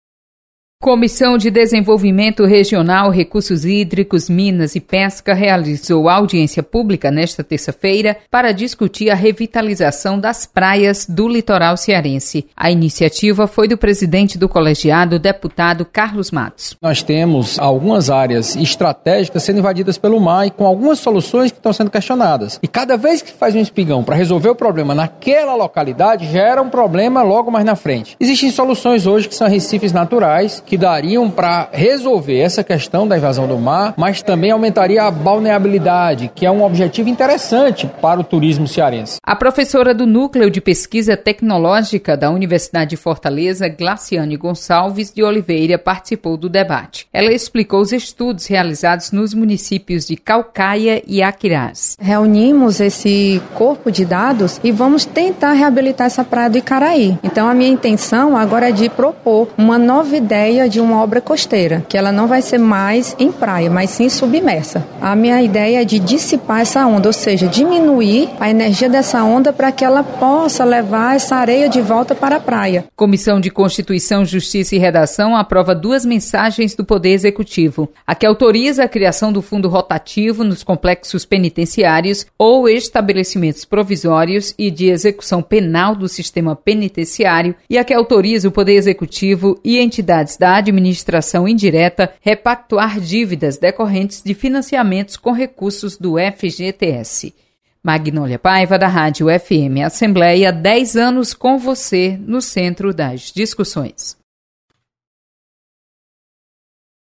Acompanhe o resumo das comissões técnicas permanentes da Assembleia Legislativa. Repórter